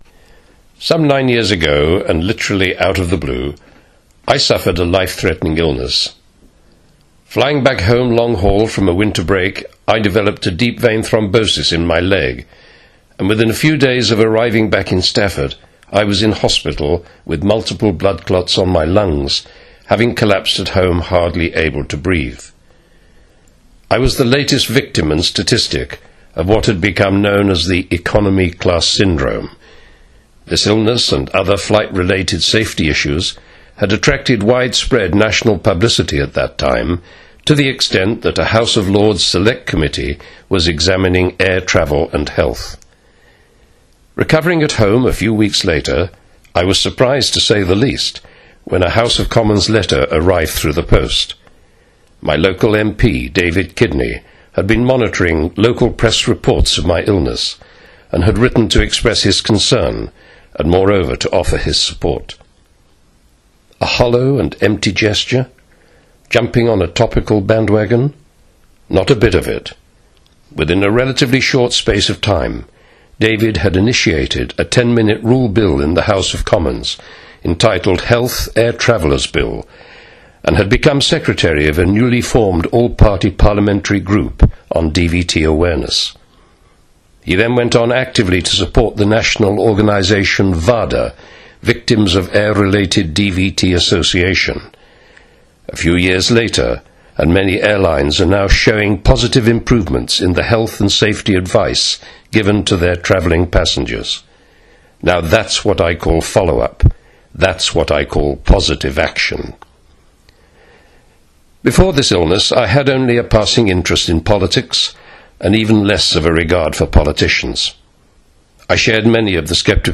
a constituent explains how his experience helped prevent DVT for other people